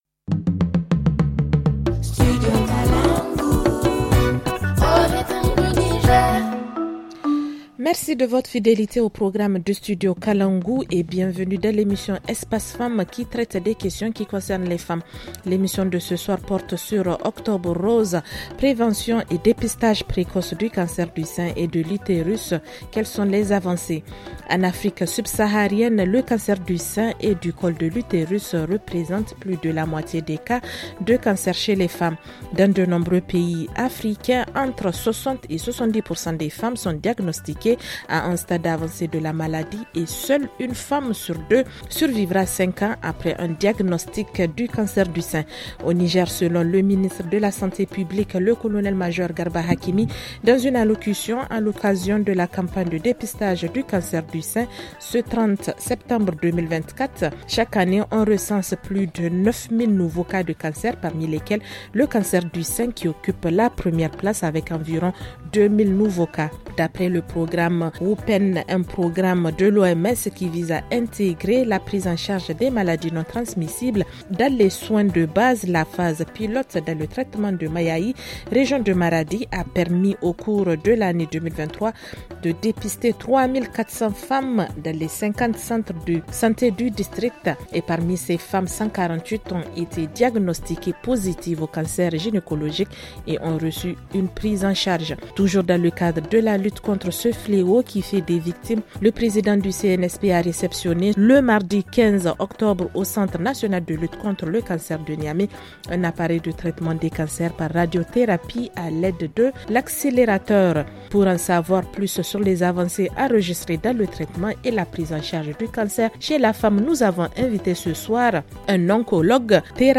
Rediffusion: Quelles sont les avancées dans la prévention et dépistage précoce du cancer du sein et du col de l’utérus ? - Studio Kalangou - Au rythme du Niger